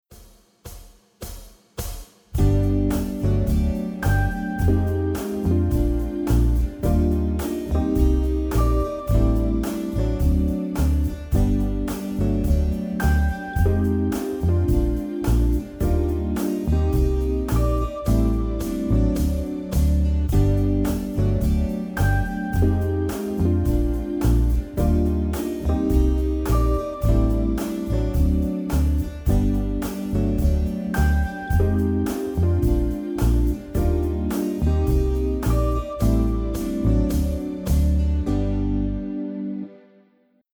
Akkordprogression med modulation og gehørsimprovisation:
Lyt efter bassen, der ofte spiller grundtonen.
Modulation: En stor terts op eller en stor terts ned
C instrument (demo)